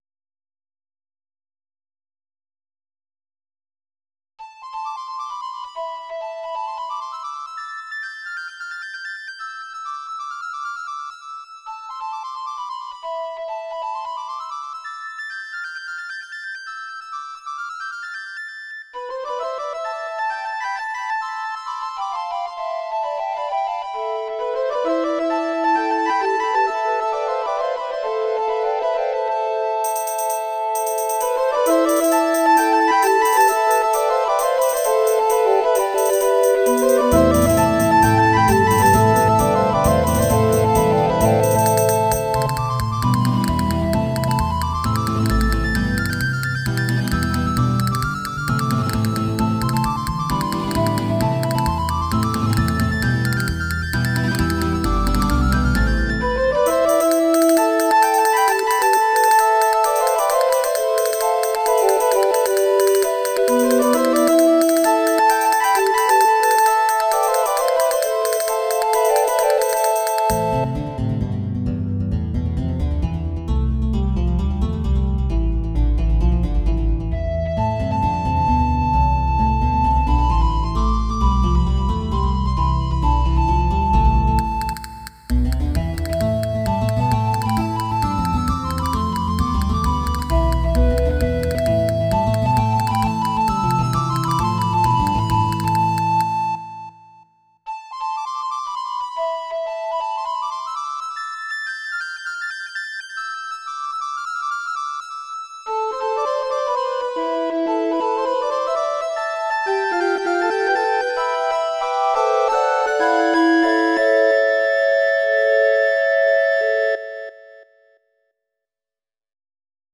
音源は全てVSC-88です。
リコーダーアンサンブルのための小品です。素朴な感じに仕上げました。